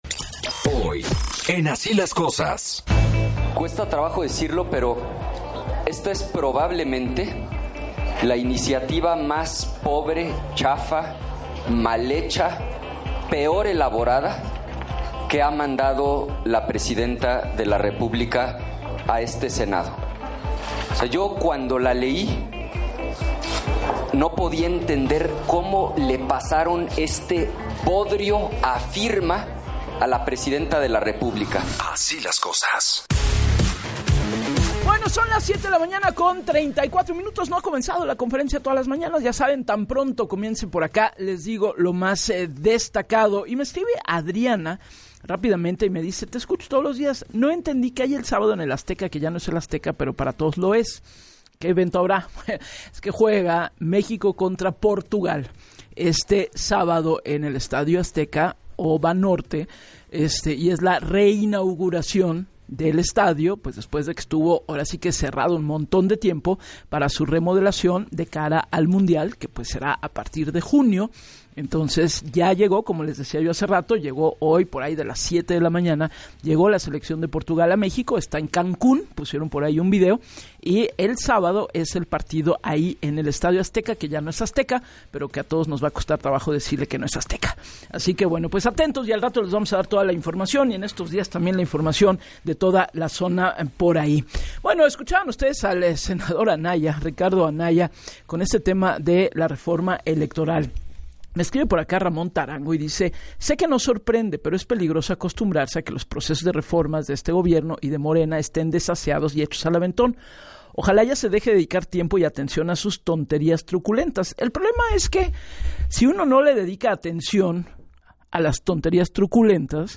En entrevista para “Así las Cosas” con Gabriela Warkentin, el consejero detalló que el comunicado a la opinión pública busca precisar lo que en redes se ha viralizado y que calificó como desinformación.